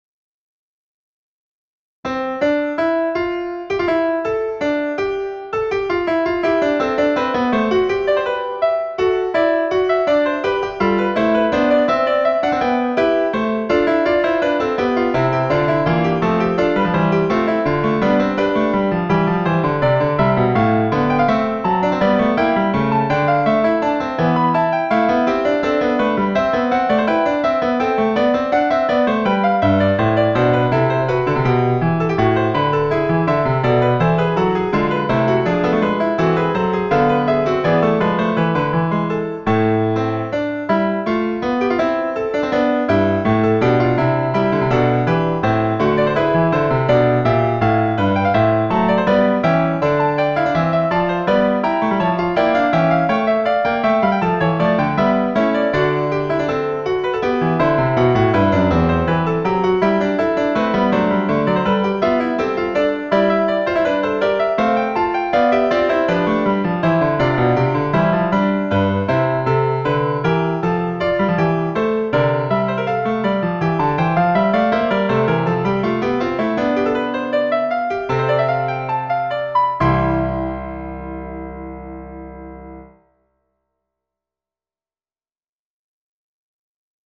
The original instrumentation is a joint from 5 voices.
The sound is generated artificially by multiplication of a track:
To use all available MIDI canals, every voice is split in an area from 3 tracks.